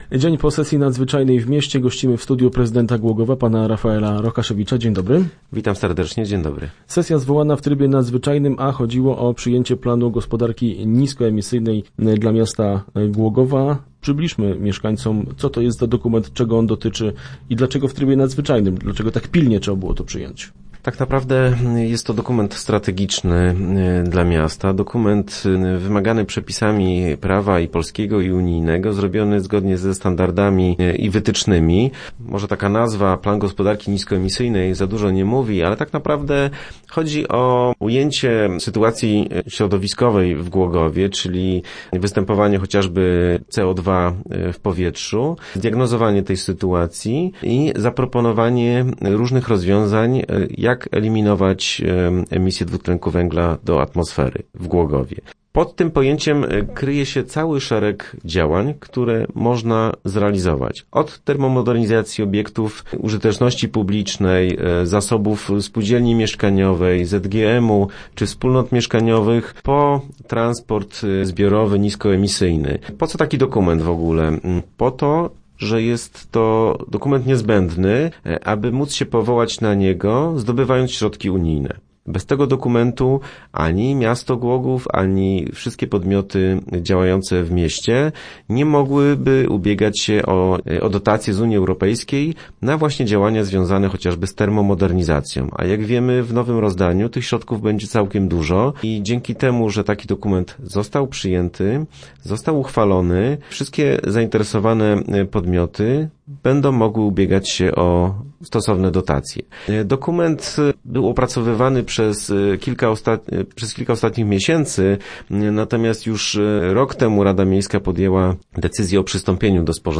- To bardzo ważny i obszerny dokument, w którym zapisano wiele inwestycji – mówił w radiowym studiu prezydent Głogowa Rafael Rokaszewicz.